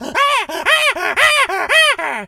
monkey_chatter_angry_10.wav